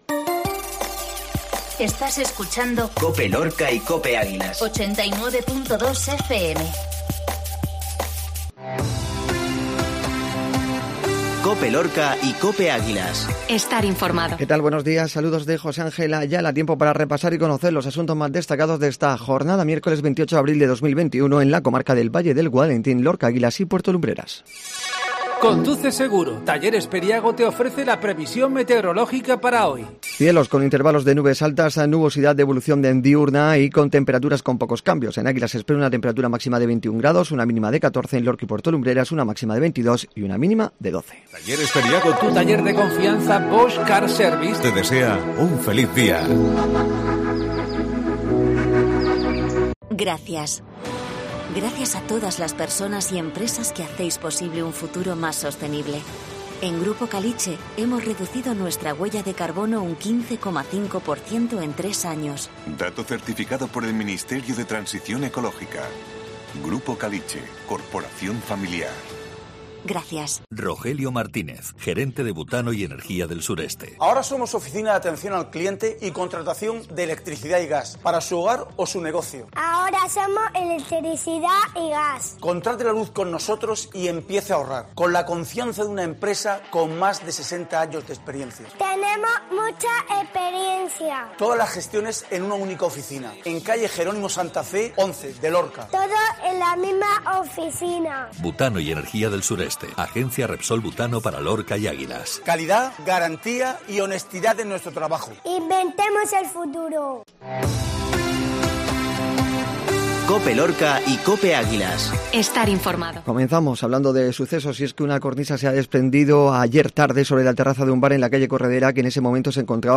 INFORMATIVO MATINAL MIÉRCOLES